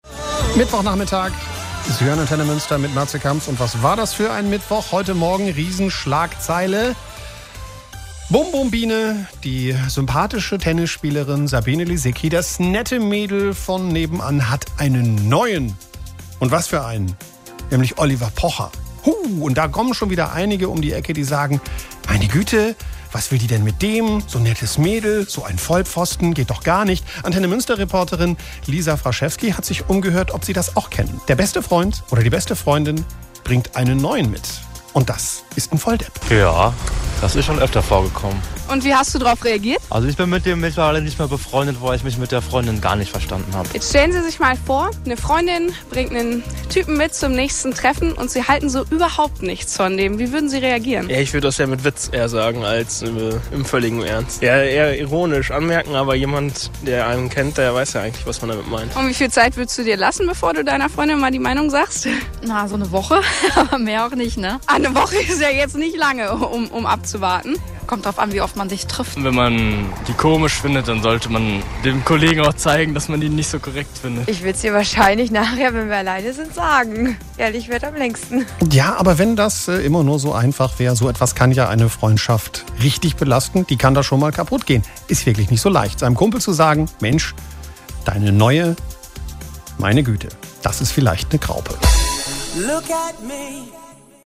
Das sorgte für Kopfschütteln: Sabine Lisicki verkündete im Dezember 2013 ihre Beziehung zu Oliver Pocher. Ich hab die Gelegenheit genutzt und auf der Straße nachgefragt, wie die Münsteraner:innen so auf den neuen Partner von guten Freunden reagieren:
Geführte-Umfrage-zum-neuen-Partner.mp3